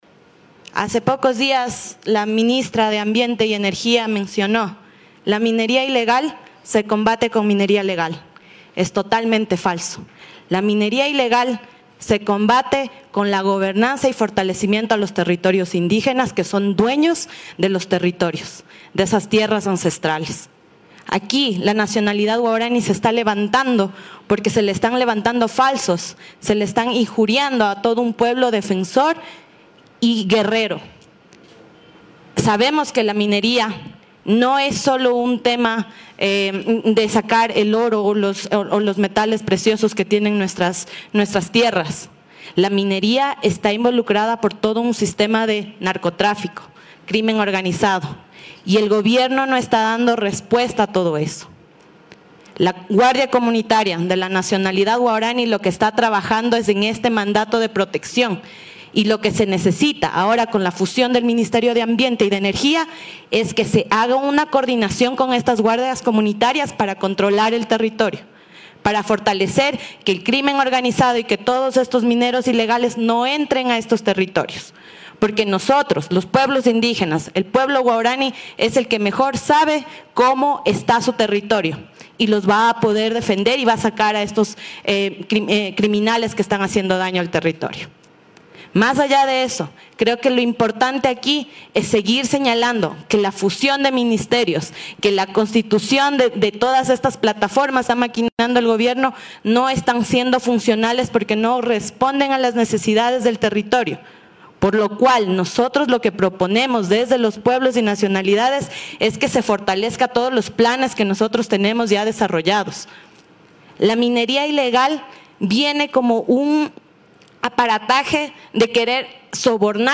Indígenas de la Nacionalidad Waorani de las provincias Pastaza, Orellana y Napo se trasladaron el 8 de septiembre hasta Quito capital del Ecuador para mediante una rueda de prensa denunciar y rechazar, a varios sectores por haberles calificado de ser parte de los grupos que realizan actividades mineras en sus territorios.